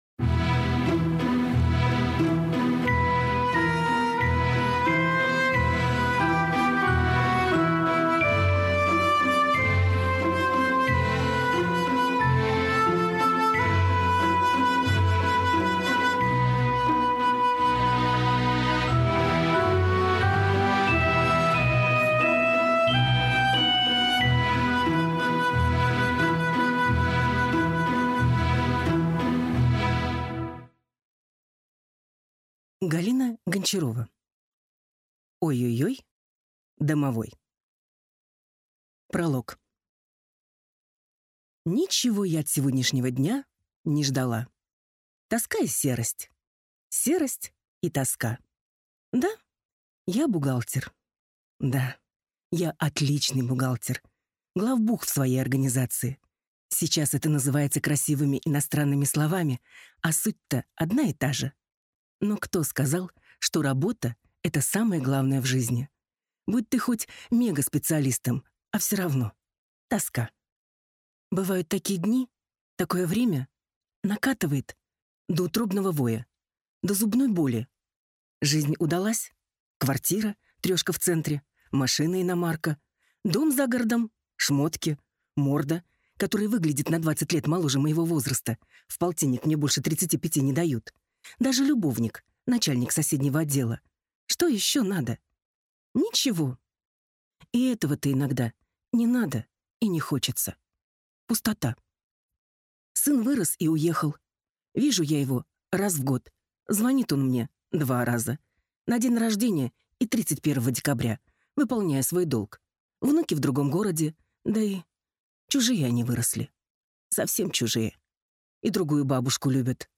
Аудиокнига Ой-ой-ой, домовой!